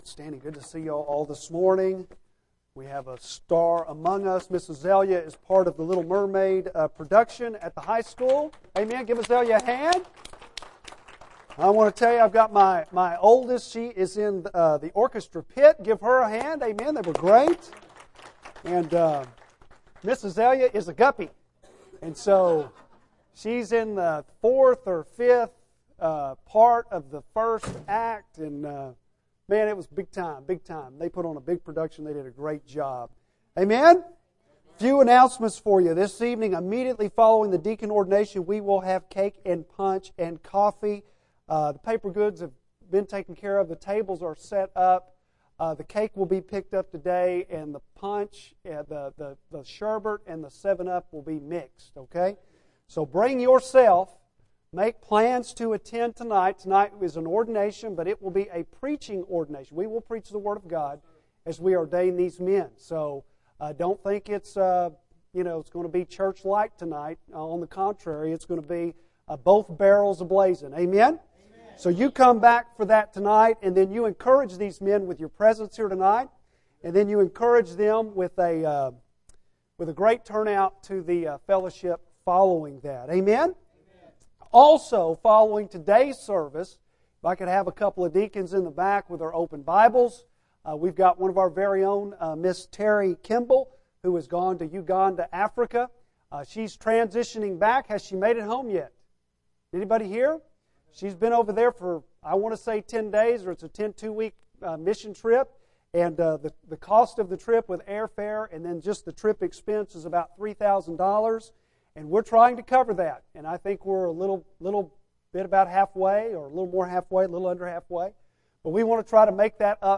Bible Text: Mark 8:10-26 | Preacher